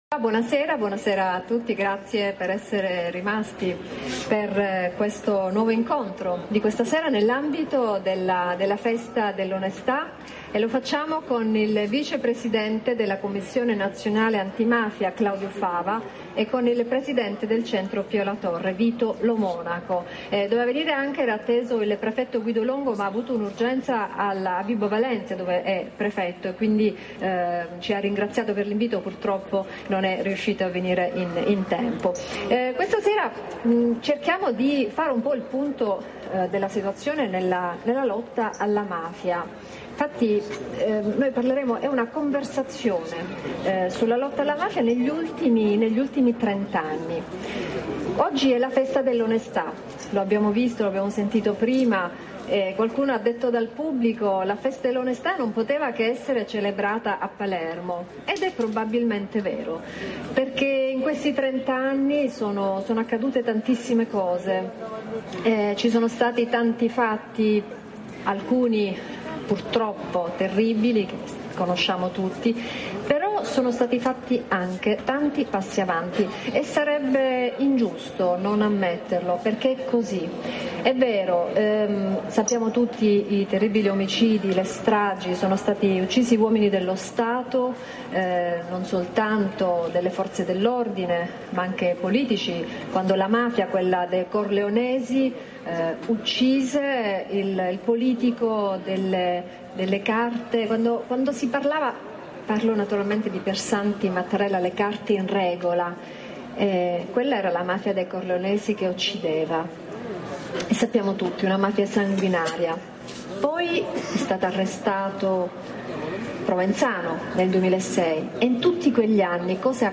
A Palermo la Festa dell'onest� in nome di Carlo Alberto Dalla Chiesa
piano della Cattedrale